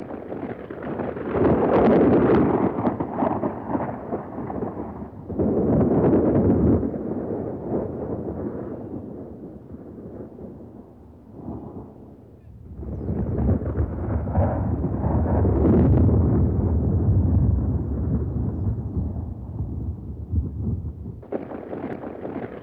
Index of /90_sSampleCDs/E-MU Producer Series Vol. 3 – Hollywood Sound Effects/Ambient Sounds/Rolling Thunder